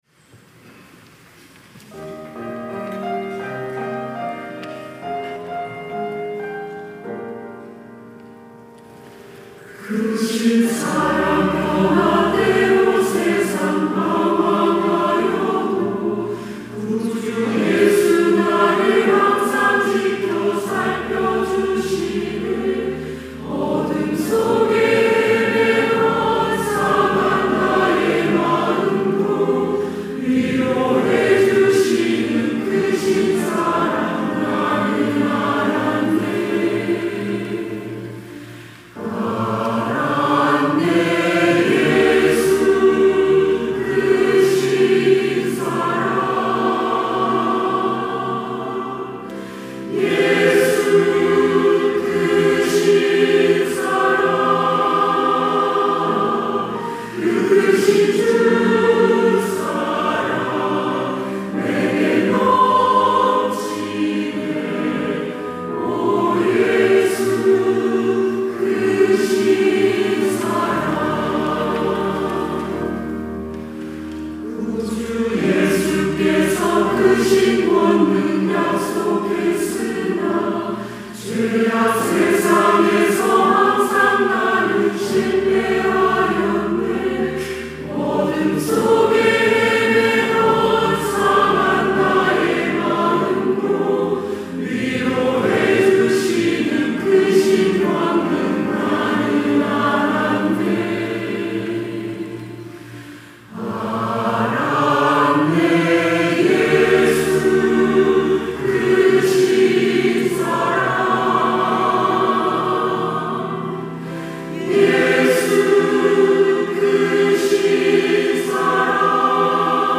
시온(주일1부) - 주님은 항상
찬양대 시온